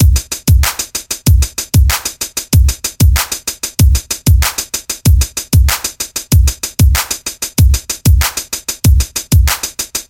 稳定的节拍
Tag: 95 bpm Electronic Loops Drum Loops 1.70 MB wav Key : Unknown